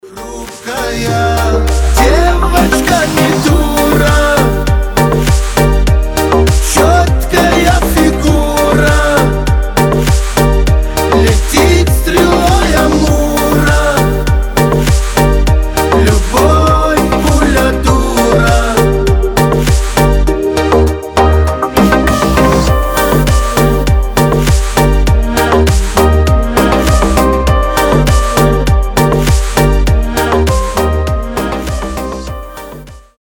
мужской голос
мелодичные